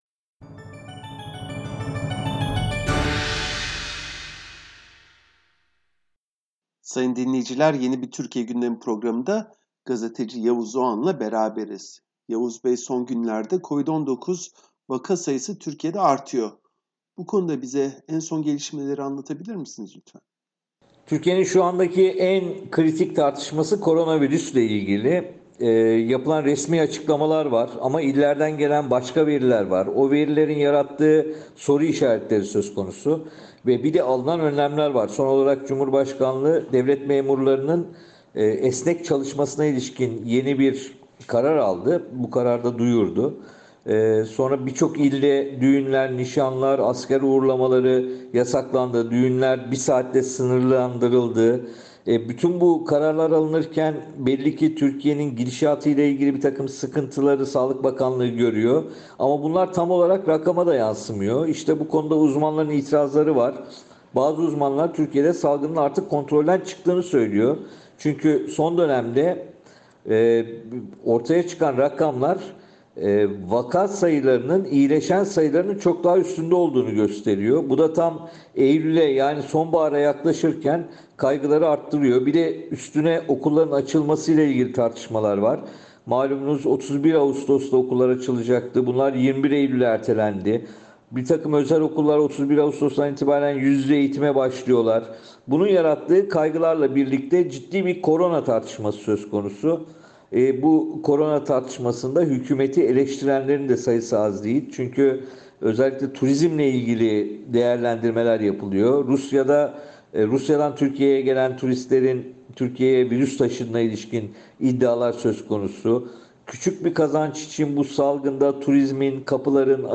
Gazeteci Yavuz Oğhan, SBS Türkçe için yaptığı değerlendirmede Türkiye'de artan COVID-19 vakalarını ve pandemi yüzünden kısıtlanan 30 Ağustos Zafer Bayramı kutlamalarını değerlendirdi.